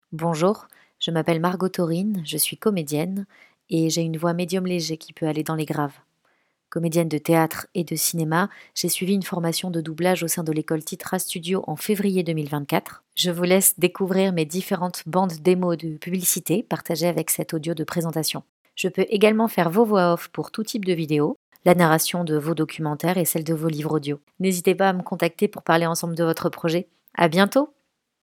Voix off
Présentation
23 - 45 ans - Mezzo-soprano